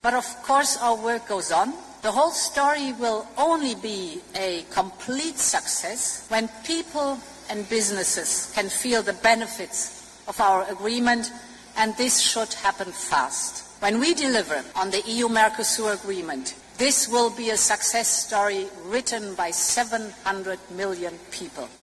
But speaking in Brazil last night, Commission President Ursula von der Leyen was focused on implementing the deal: